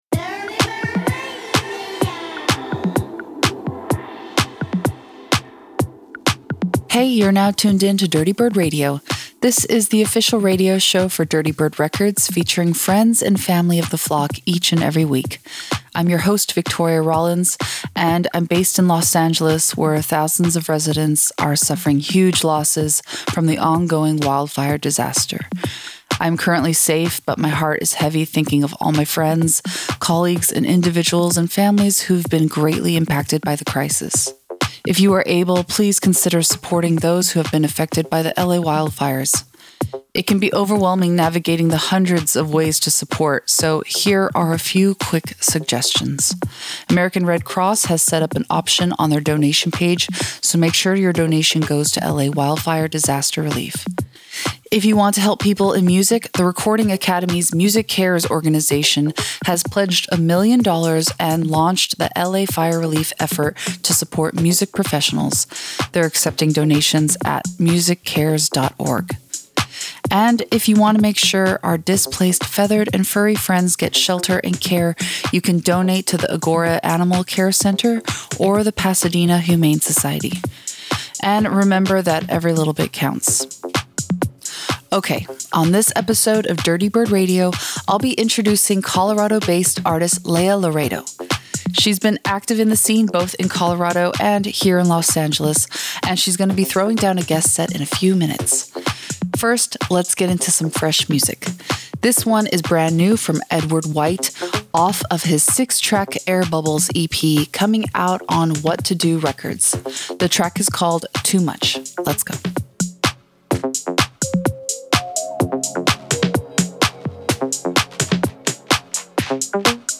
alternative bass music to funk based house and electronica
Electronic